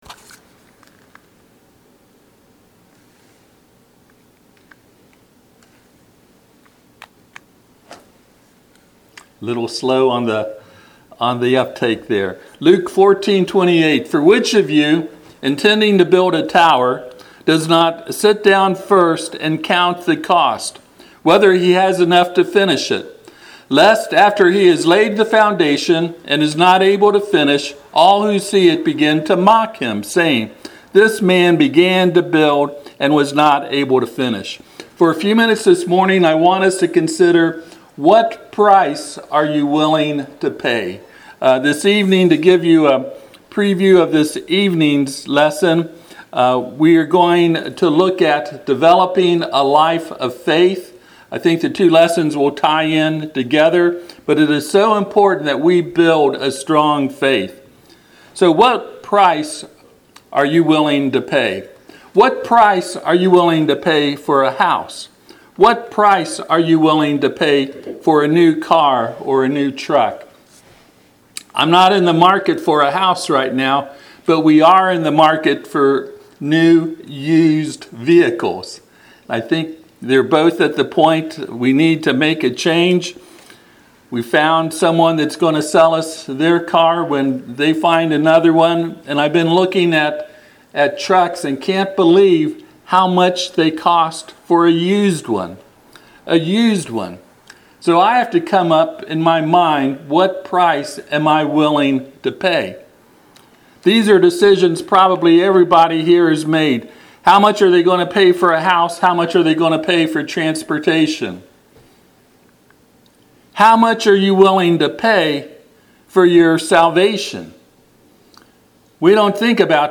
Passage: Luke 14:28-30 Service Type: Sunday AM